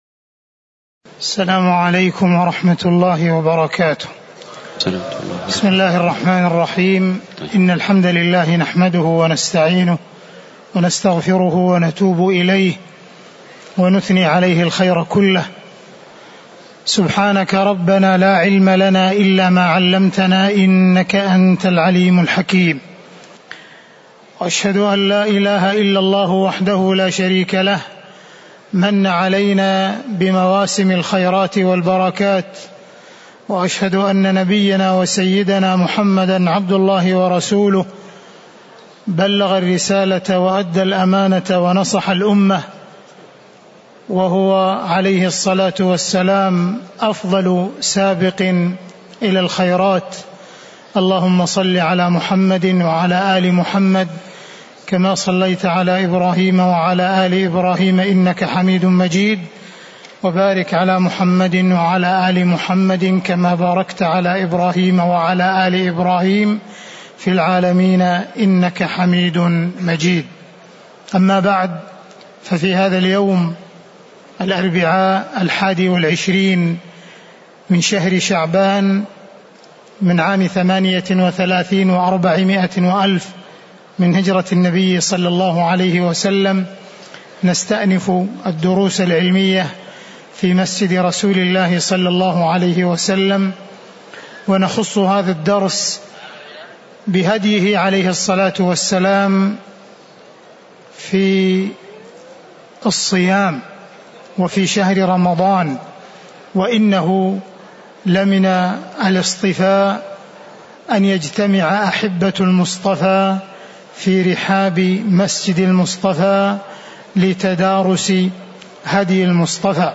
تاريخ النشر ٢١ رمضان ١٤٣٨ هـ المكان: المسجد النبوي الشيخ: معالي الشيخ أ.د. عبدالرحمن بن عبدالعزيز السديس معالي الشيخ أ.د. عبدالرحمن بن عبدالعزيز السديس فصل في هديه صلى الله عليه وسلم في رمضان (021)